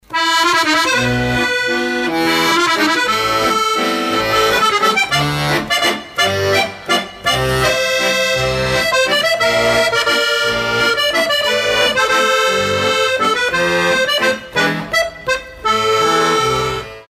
Звуки аккордеона
2. Тонкая мелодия